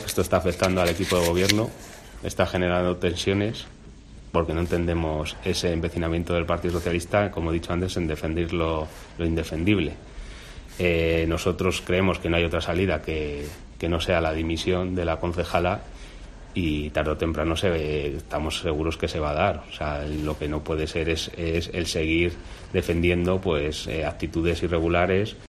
El portavoz municipal de IU en el Ayuntamiento de Segovia, Ángel Galindo